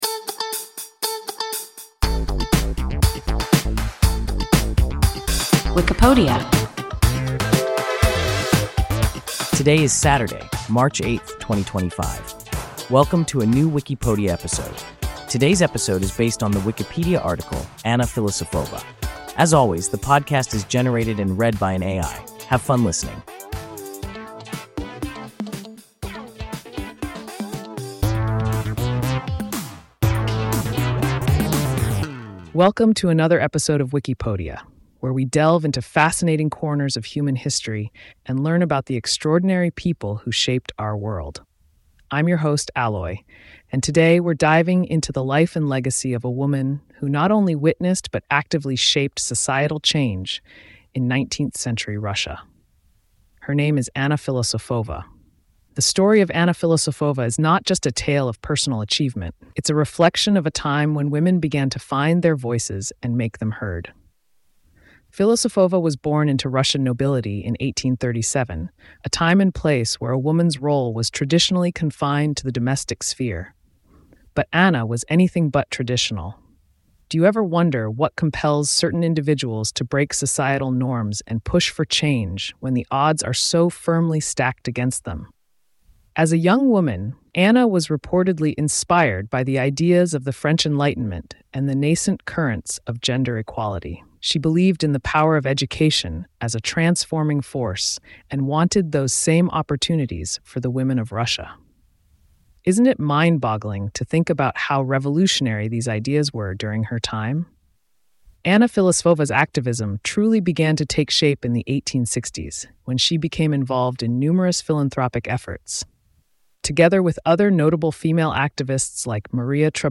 Anna Filosofova – WIKIPODIA – ein KI Podcast